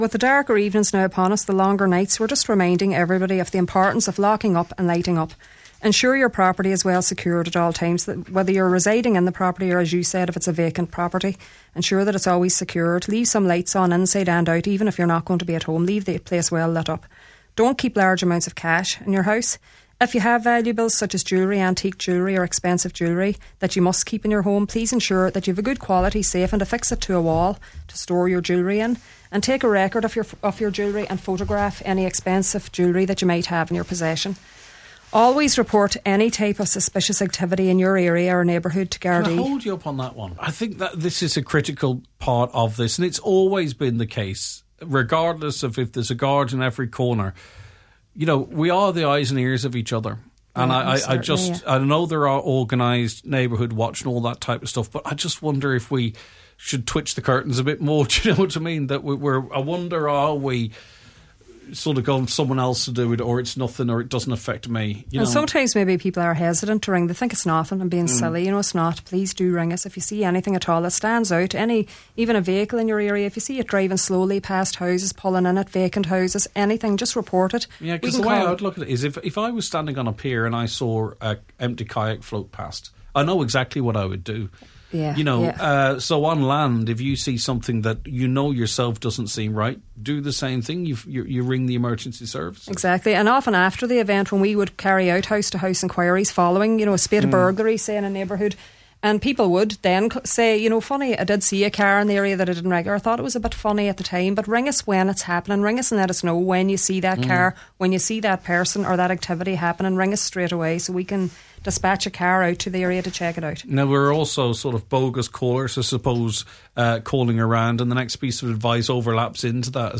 On today’s Nine til Noon Show, Garda